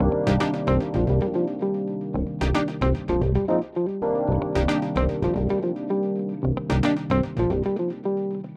06 Backing PT1.wav